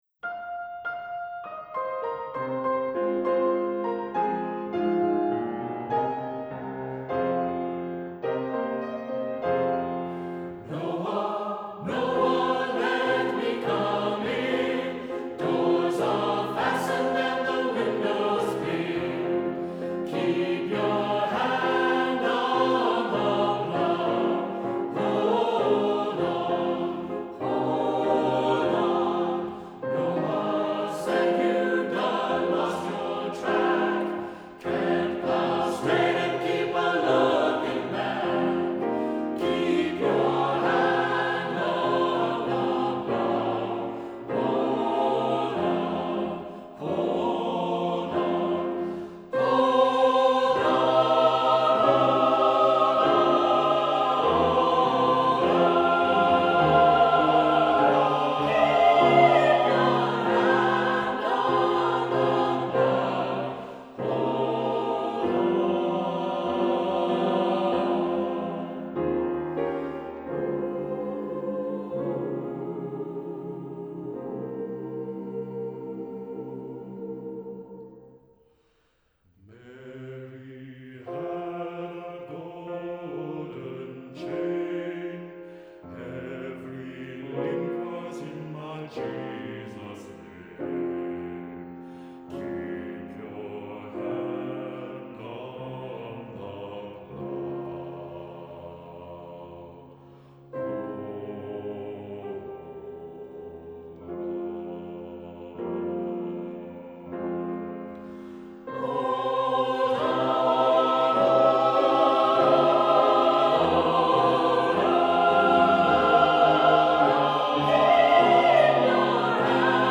Composer: Spiritual
Voicing: SATB